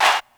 [vox] murda gang.wav